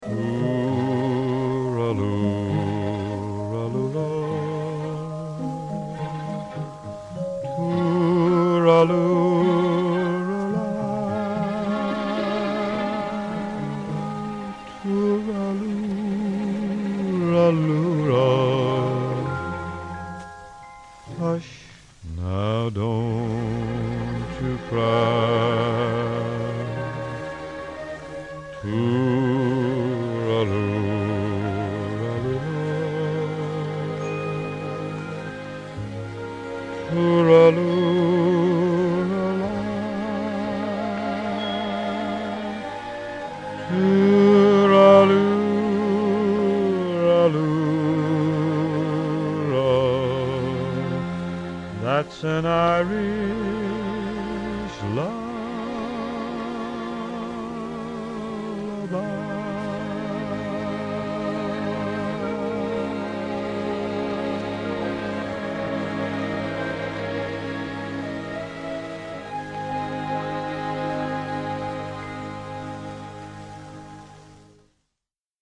an Irish Lullabye
years ago i saw a movie and one scene was this old priest meeting his mother for the first time in 40 years .... the background music was the sweetest melody you ever heard.... the song was